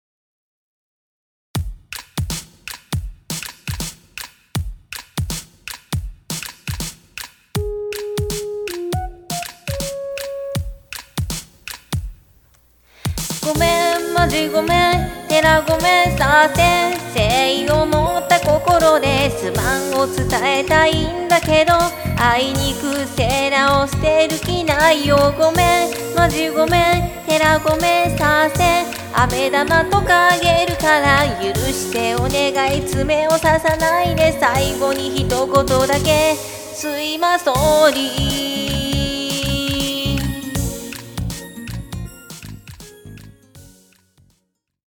♪---ショタ寄り